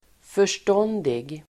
Uttal: [för_st'ån:dig]